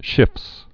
(shĭfs)